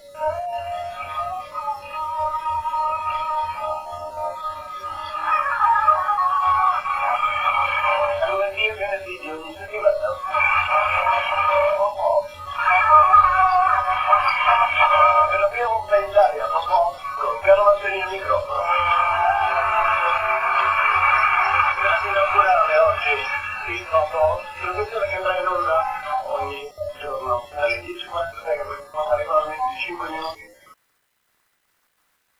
Il programma è Popoff, emanazione di Per voi giovani (in buona parte i conduttori sono gli stessi). Questa, fortunosamente recuperata (scusate la scarsa qualità) è la presentazione della serata inaugurale del programma, il 22 ottobre del 1973, con Carlo Massarini al microfono. Come tutte le trasmissioni serali (la Rai non cambia mai) la trasmissione partiva già con il ritardo incorporato, come sottolinea lo stesso Massarini.